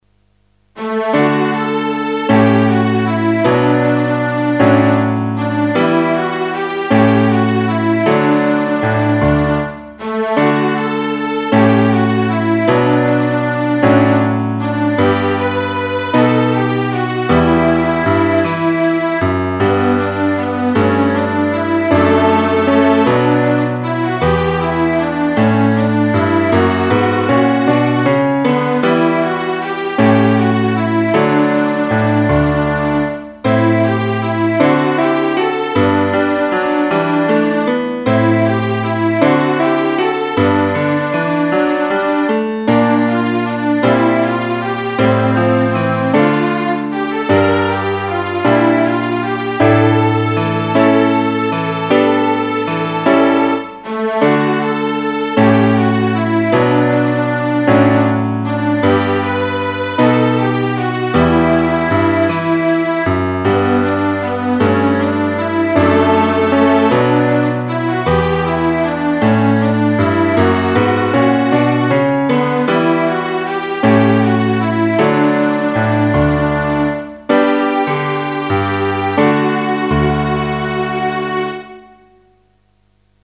音色：ストリングス＋ピアノ/設定：標準（リズムなし）